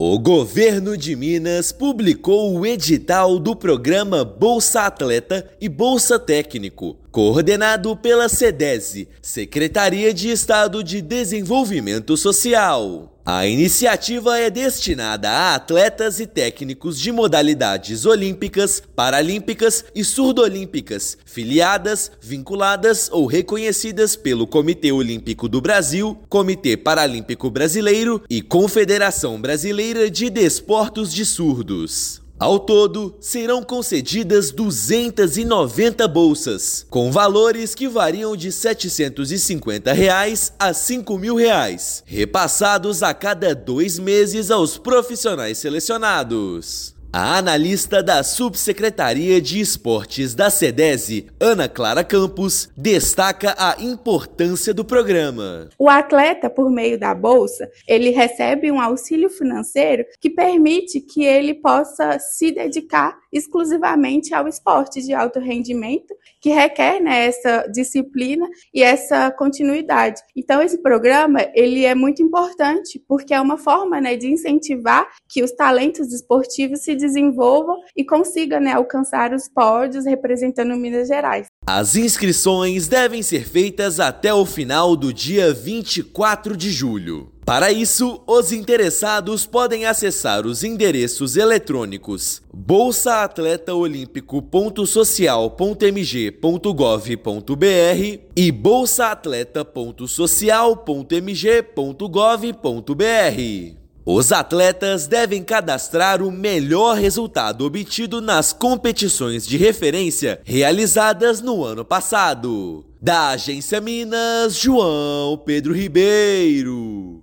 Interessados tem até o dia 24/7 para se inscreverem . Ouça matéria de rádio.